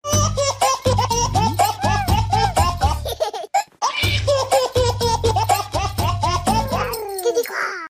ha ha haha meme sound effect
ha-ha-haha-meme-sound-effect-.mp3